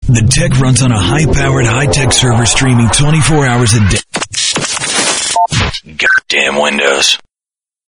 RADIO IMAGING / HOT AC